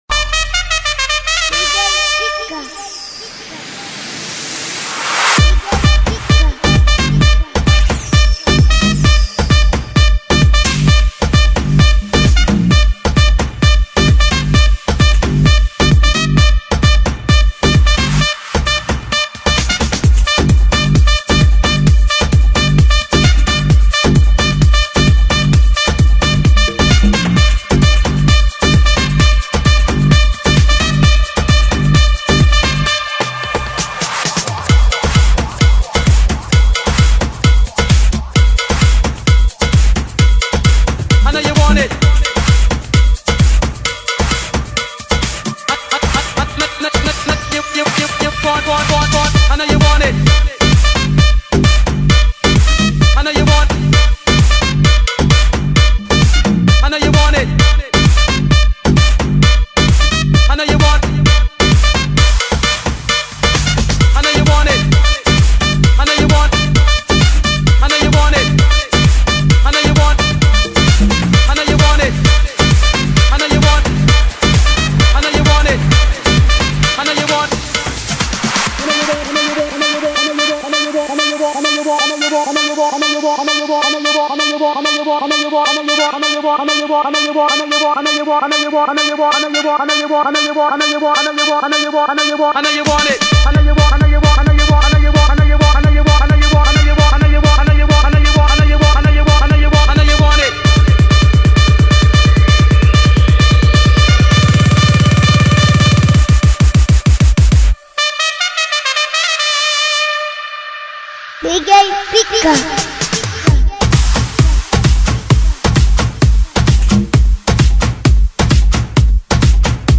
KFff4kcMaGR_Musica-de-circo-MALABARES.mp3